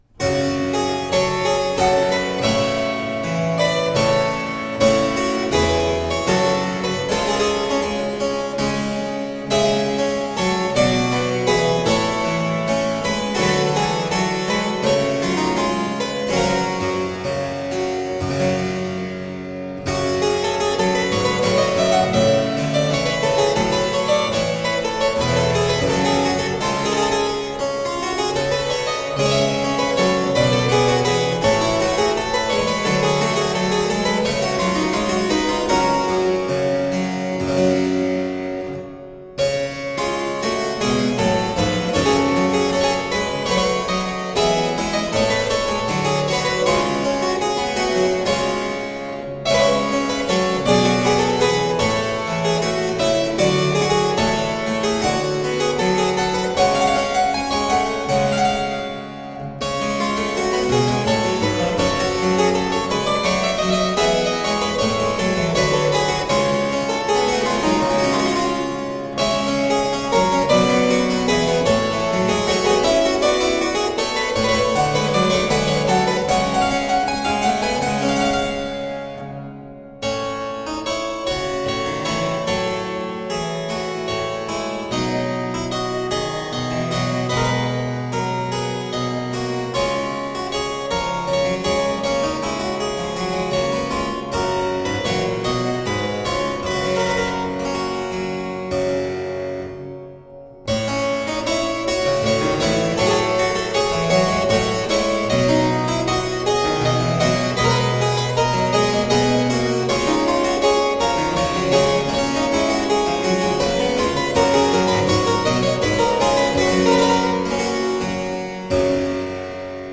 Deeply elegant harpsichord.